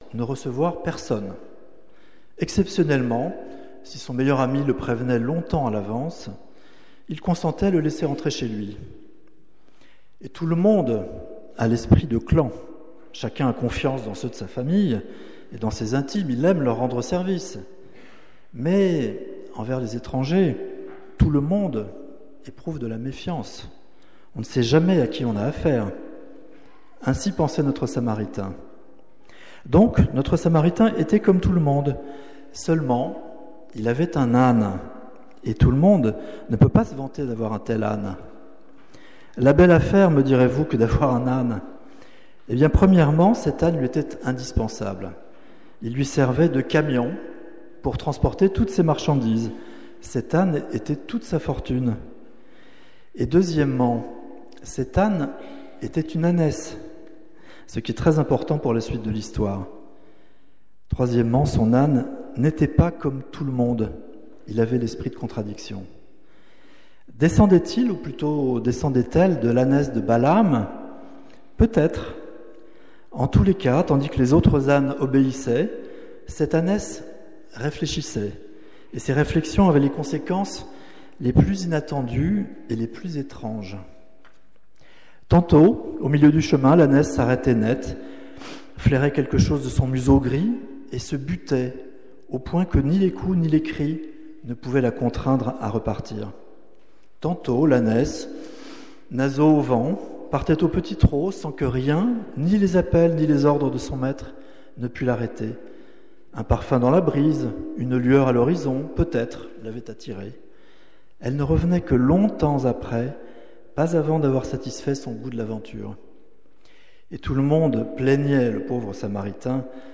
Culte de Noël
Eglise Protestante Unie de la Bastille
Culte du 15 décembre 2024